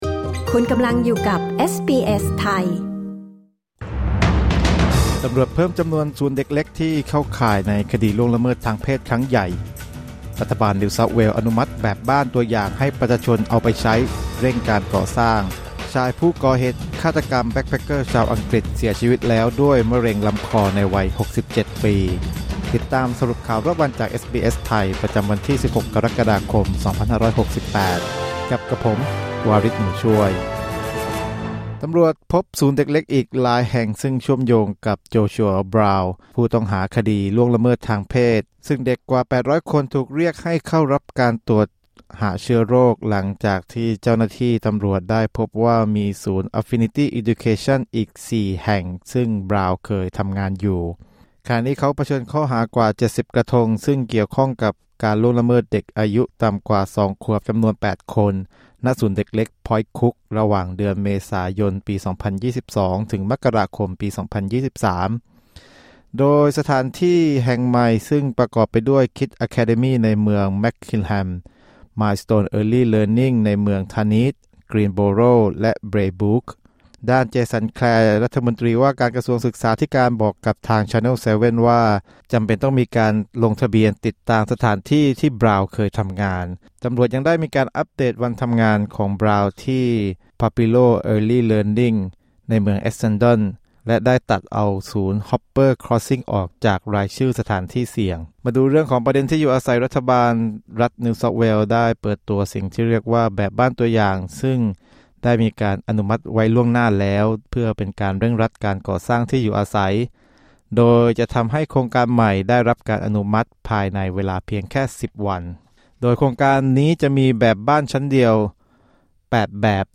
สรุปข่าวรอบวัน 16 กรกฎาคม 2568